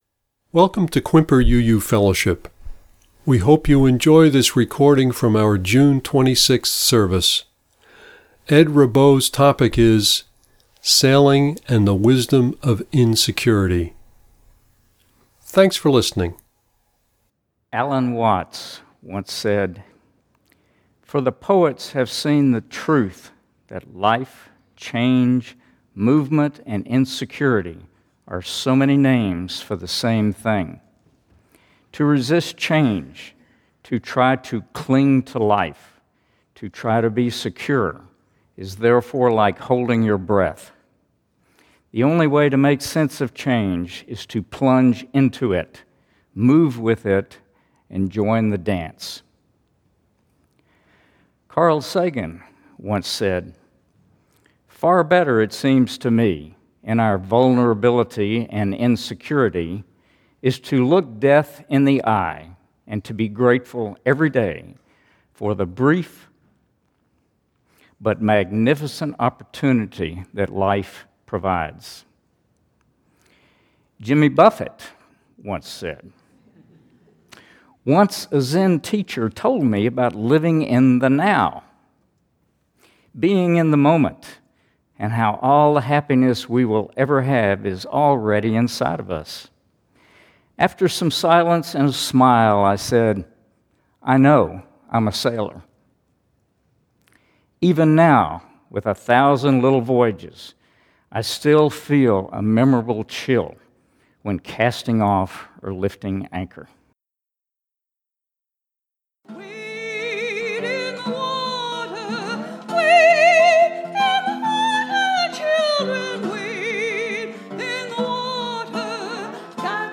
Listen to the reading and sermon here.